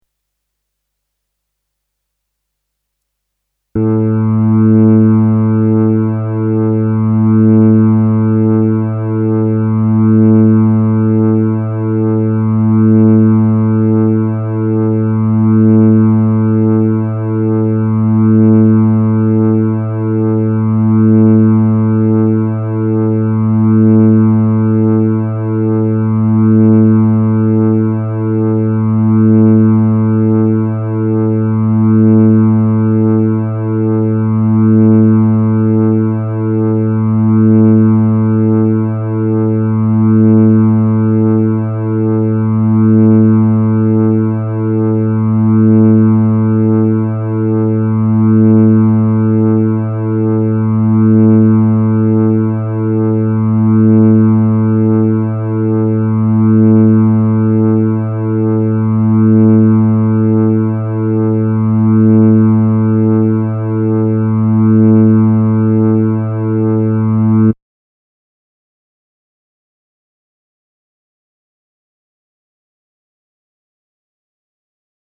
Tuning notes
Here are synthesised notes, each about a minute long, for you to check your guitar tuning to.  These are for standard tuning, sometimes known as concert tuning.
5th string A
Fifth String A.mp3